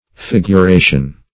Figuration \Fig`u*ra"tion\, n. [L. figuratio.]